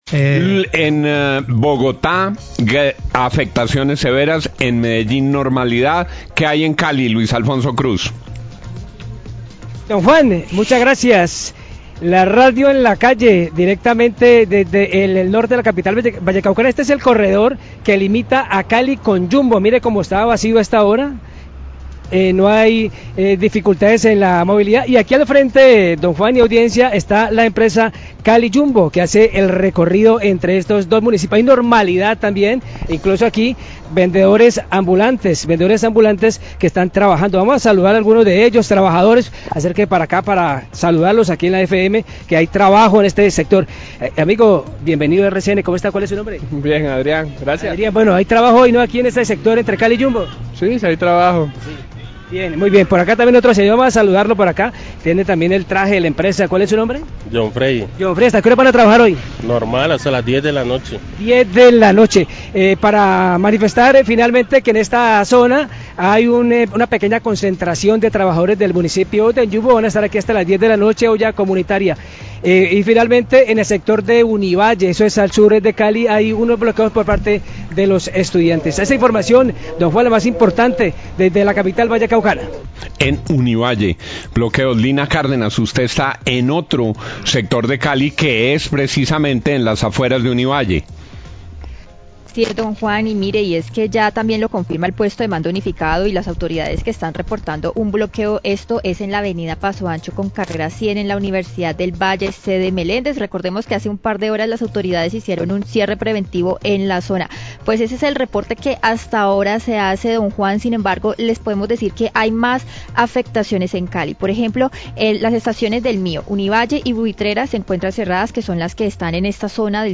Reporte de la situación de la jornada de paro en Cali, bloqueo en Univalle, La FM, 845am
Radio